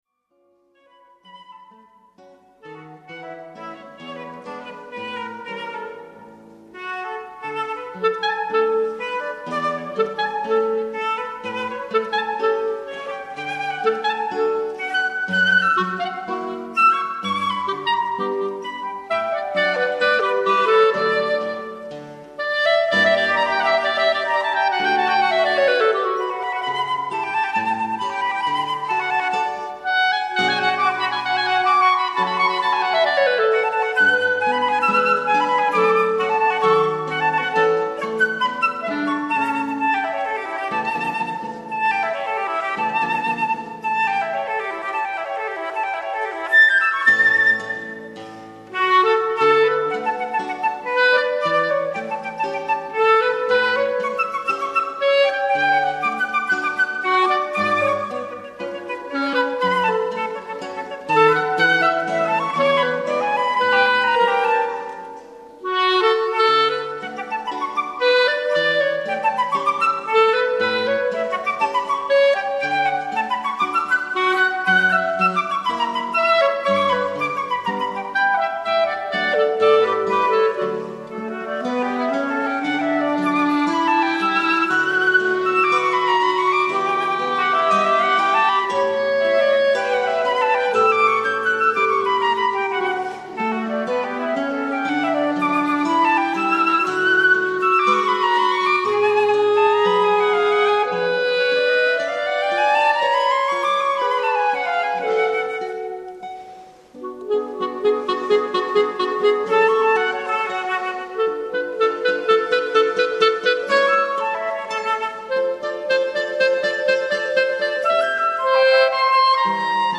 Fantasia op. 110 on la Gioconda di Ponchielli (live) sample 3'20'' (Trio del Garda)